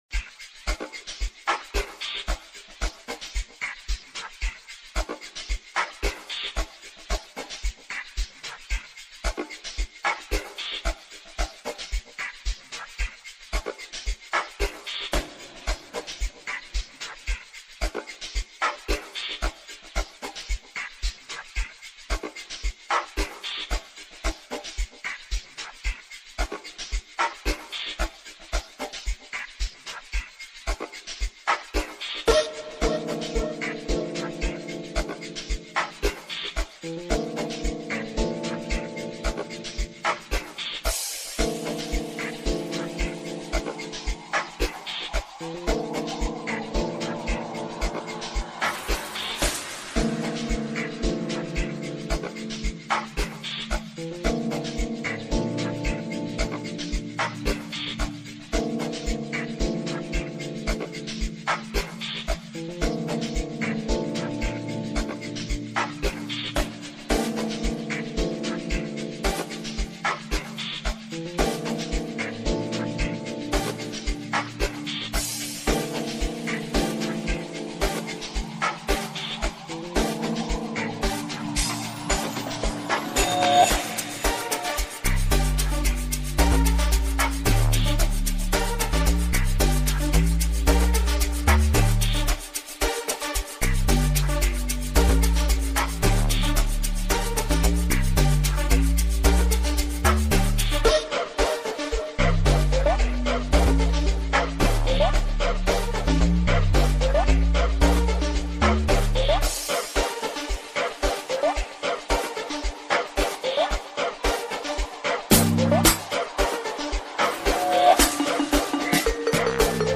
06:25 Genre : Amapiano Size